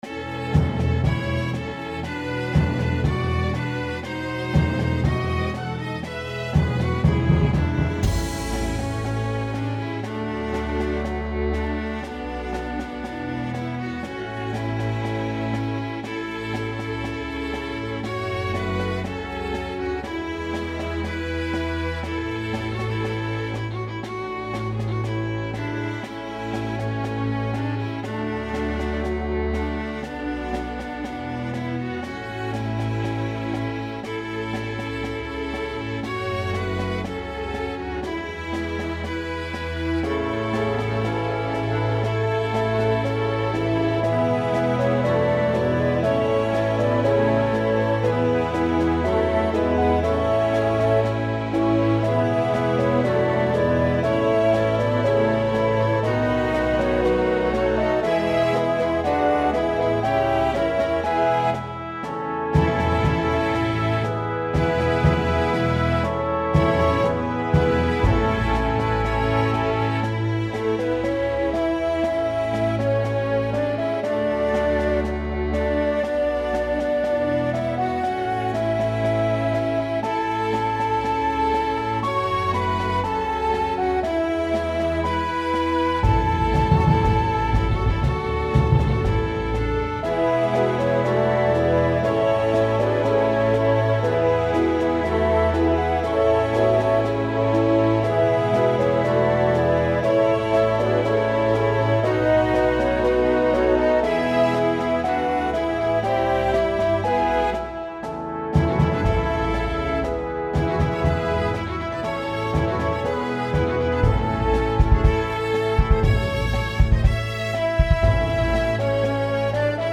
MP3 Instrumental Backing